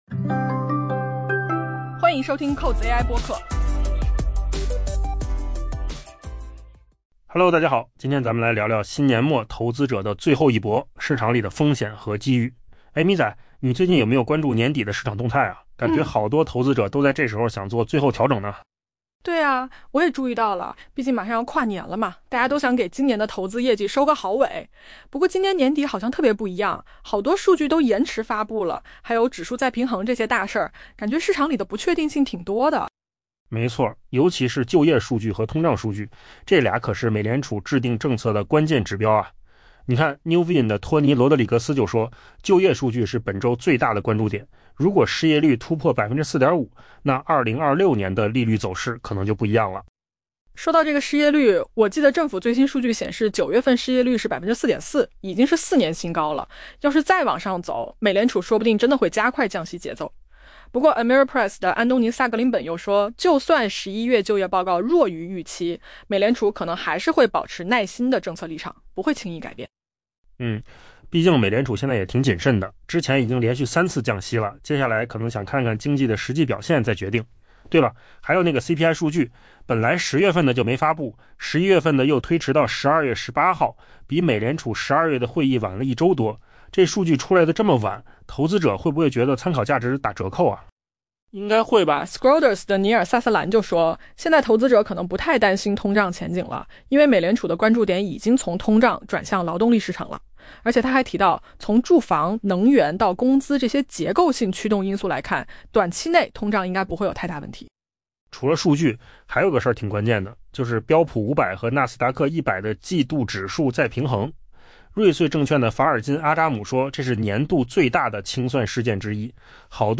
AI 播客：换个方式听新闻 下载 mp3 音频由扣子空间生成 对于希望在新年到来前进行 「最后一搏」 的投资者而言，留给他们的时间已经不多了。